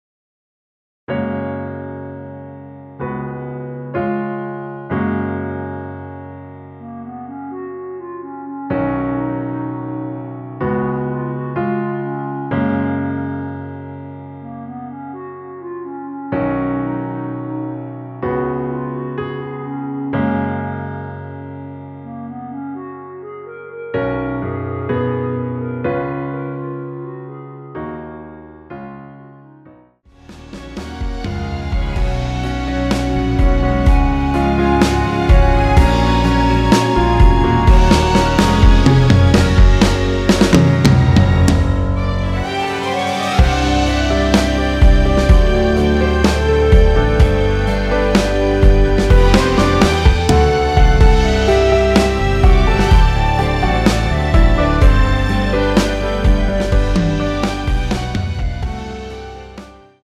전주 없이 시작하는 곡이라 전주 만들어 놓았습니다.(미리듣기 확인)
원키에서(-3)내린 멜로디 포함된 MR입니다.
Gb
앞부분30초, 뒷부분30초씩 편집해서 올려 드리고 있습니다.
중간에 음이 끈어지고 다시 나오는 이유는
(멜로디 MR)은 가이드 멜로디가 포함된 MR 입니다.